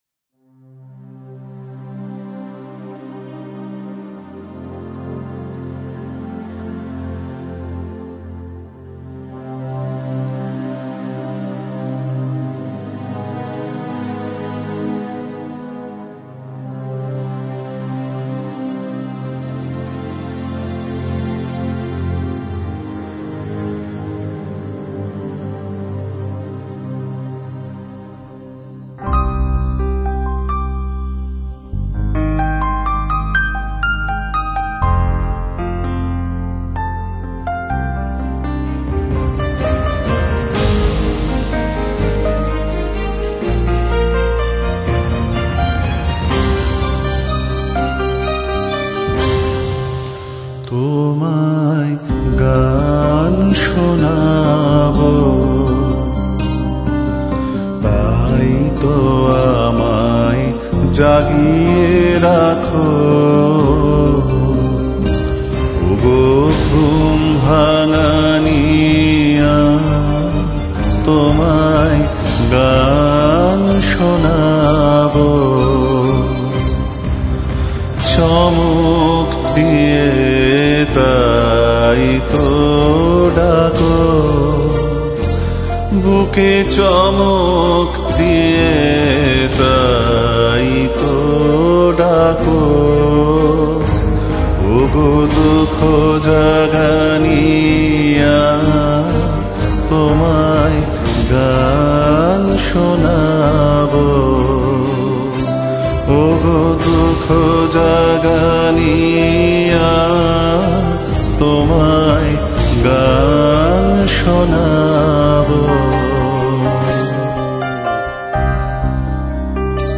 Bass, Acoustic and Electric Guitar
Orchestral Kit